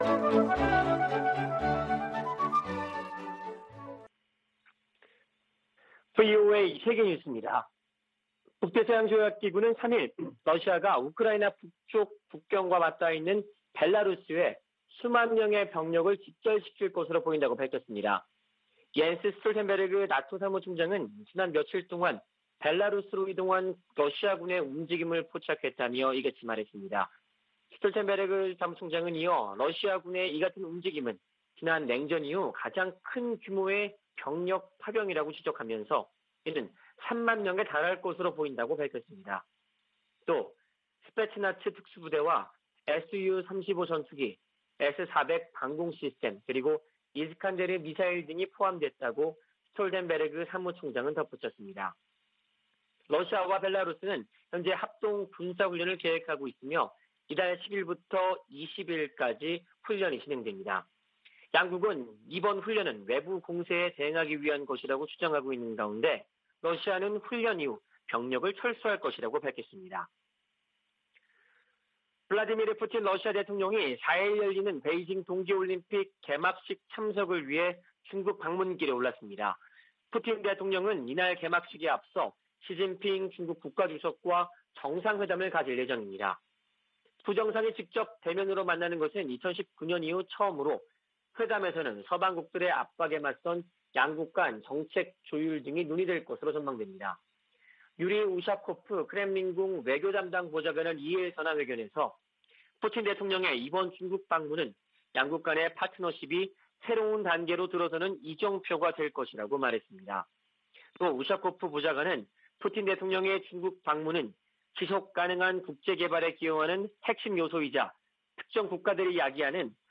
VOA 한국어 아침 뉴스 프로그램 '워싱턴 뉴스 광장' 2021년 2월 4일 방송입니다. 미한 연합훈련 연기 가능성과 관련해 한반도 준비태세를 진지하게 받아들인다고 미 국방부가 밝혔습니다. 미한 외교장관들은 북한의 미사일 능력 고도화에 우려하며, 한반도 문제는 외교적으로 해결해야 한다는 원칙을 재확인했습니다. 오는 6일 개최하는 북한 최고인민회의에 김정은 국무위원장이 등장할지, 어떤 대외 메시지를 발신할지 관심이 모이고 있습니다.